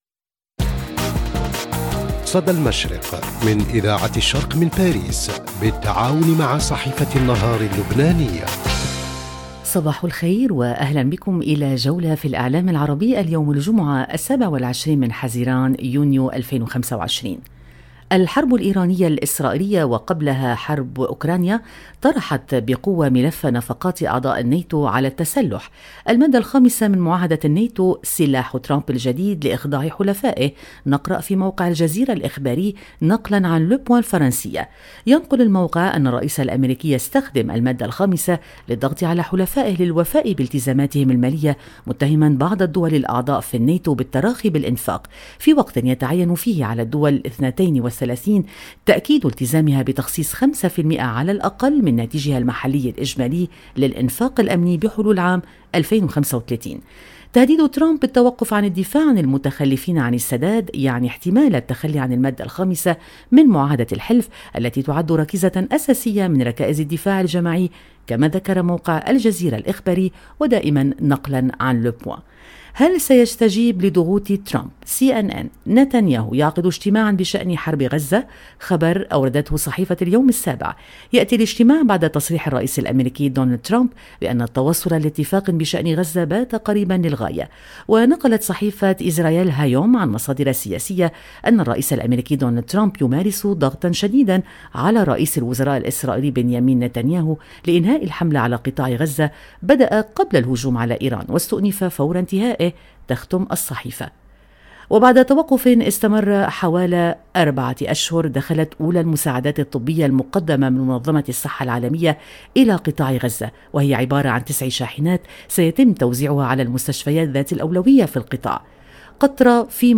صدى المشرق – نافذتك اليومية على إعلام الشرق، كل صباح على إذاعة الشرق بالتعاون مع جريدة النهار اللبنانية، نستعرض أبرز ما جاء في صحف ومواقع الشرق الأوسط والخليج من تحليلات ومواقف ترصد نبض المنطقة وتفكك المشهد الإعلامي اليومي.